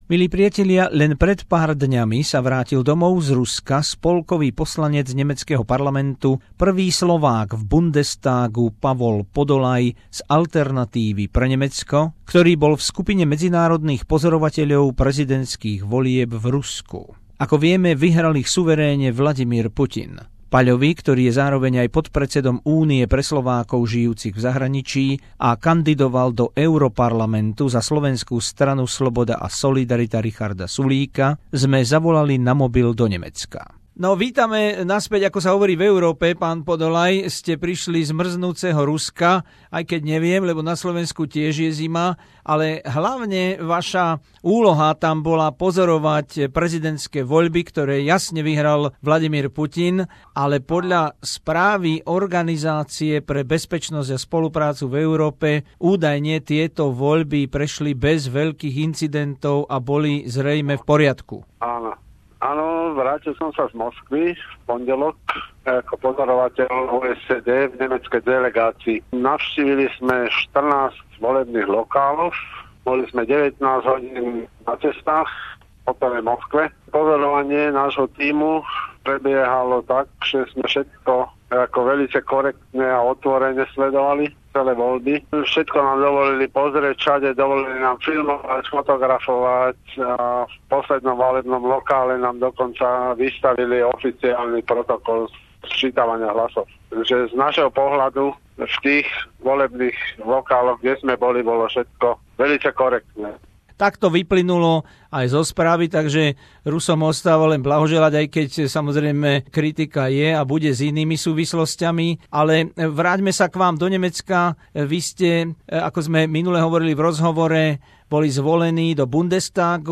Rozhovor s historicky prvým slovenským poslancom nemeckého spolkového parlamentu Pavlom Podolayom o jeho misii v Rusku v skupine medzinárodných pozorovateľov prezidentských volieb v Moskve, o novej nemeckej vláde a politike v Bundestagu a o krízovej situácii na Slovensku